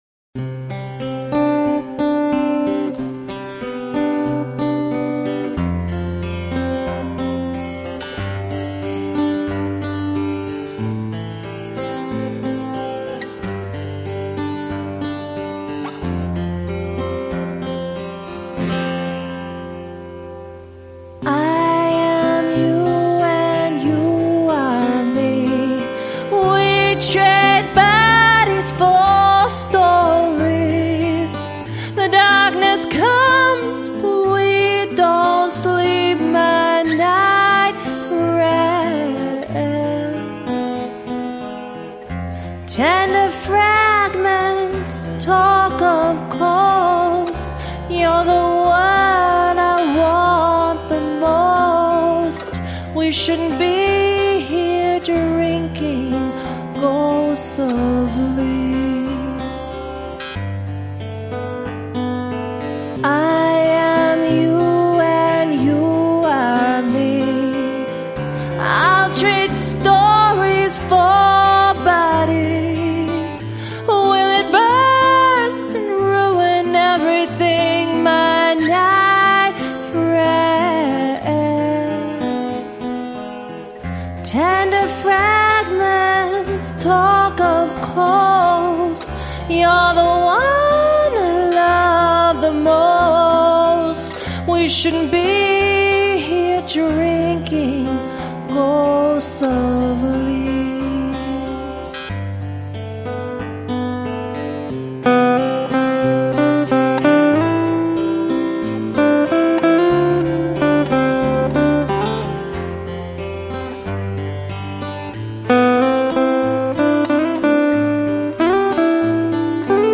原声吉他用Walden钢弦录制。
DADGAD。
女声 原声 吉他 慢板 寒颤 民谣